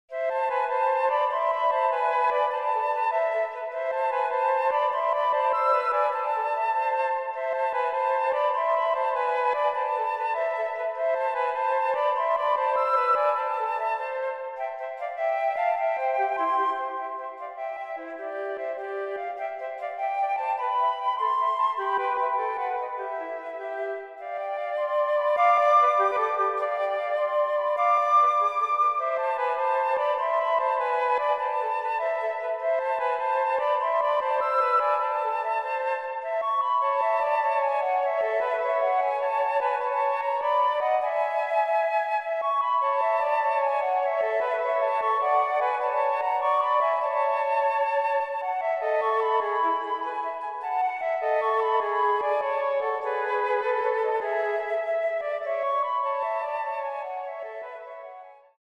für 2 Flöten
Andante - Prière